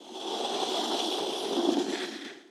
SFX_Door_Slide_05.wav